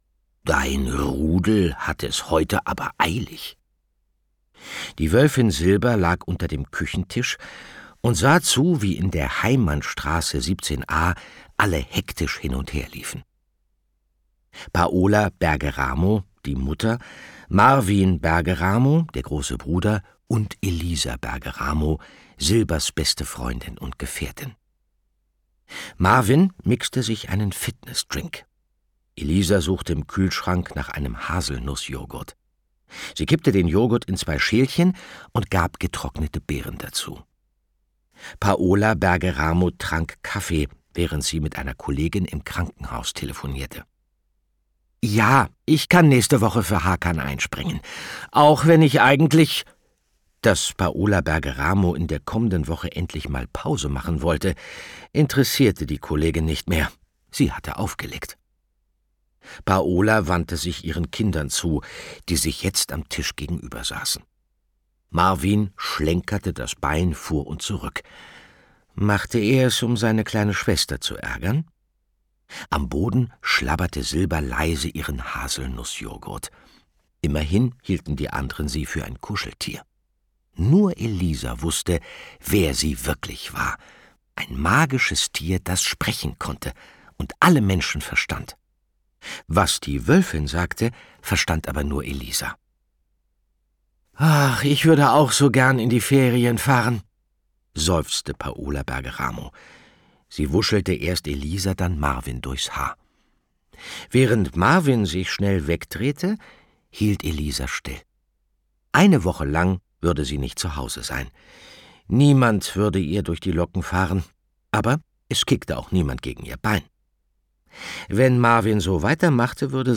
Andreas Fröhlich (Sprecher)
2024 | 2. Auflage, Gekürzte Ausgabe